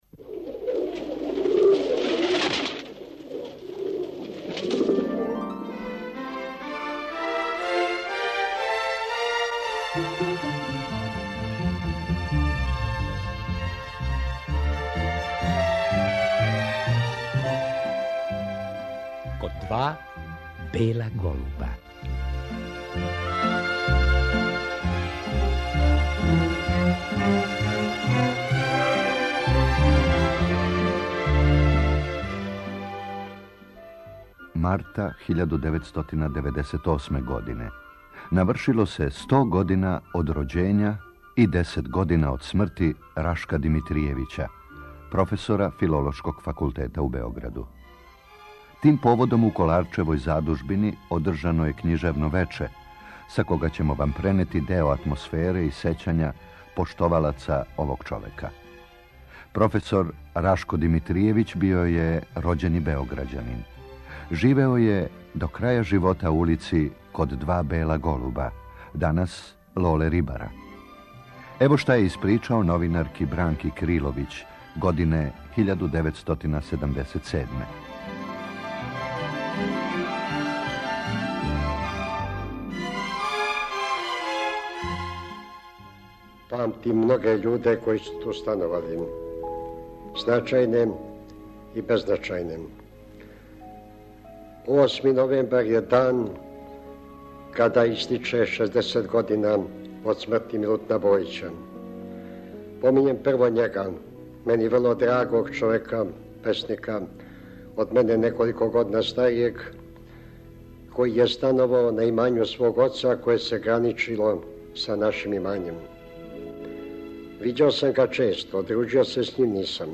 Поводом годишњице рођења Рашка Димитријевића, 15. марта 1898., слушаћемо снимак са књижевне вечери која је уприличена поводом стогодишњице његовог рођења у Коларчевој задужбини. Професор Рашко Димитријевић одржао је преко 500 предавања у нашој земљи, а од 1937. око 50 предавања на Коларчевом народном универзитету.